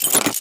opencage.ogg